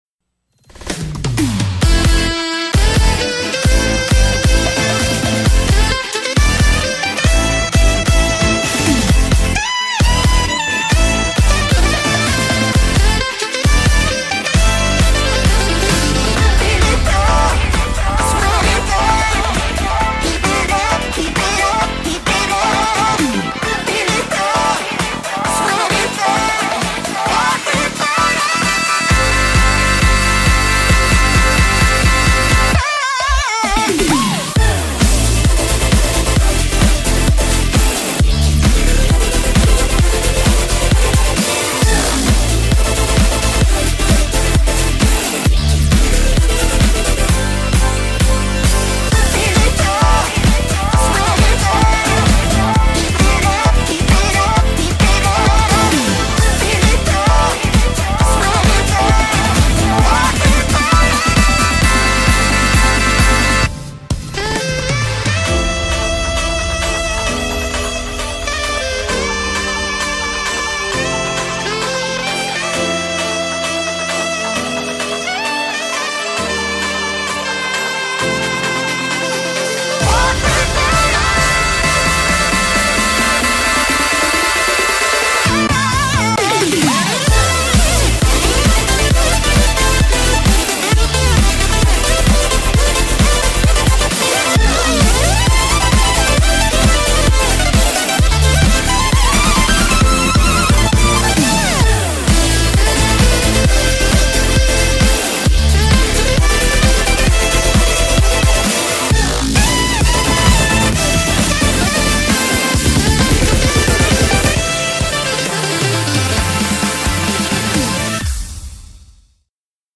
BPM132
Audio QualityPerfect (Low Quality)